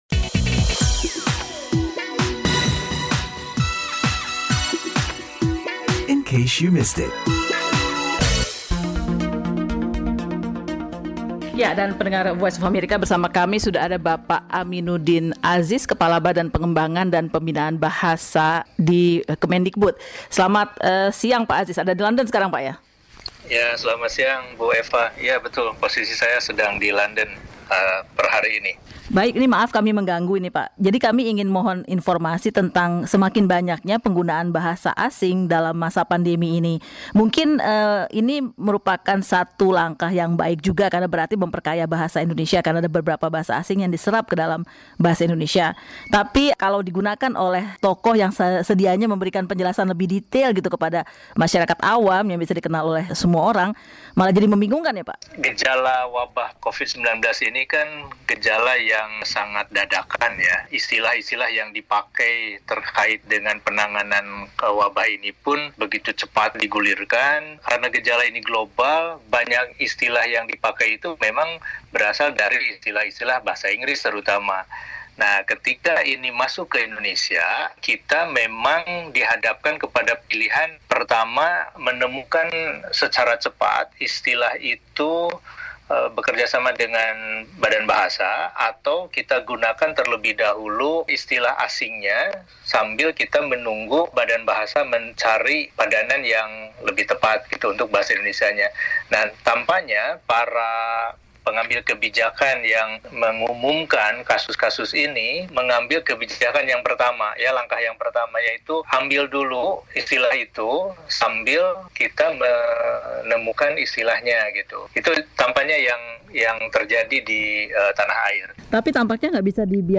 berbincang dengan Kepala Badan Bahasa Kemendikbud Prof. Dr. Aminuddin Aziz untuk membahas hal itu.